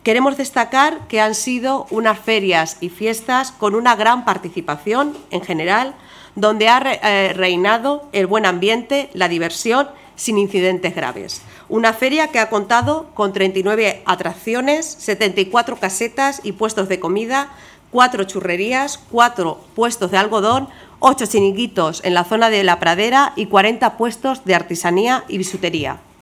Manela Nieto, concejala de festejos